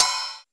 DrRide.wav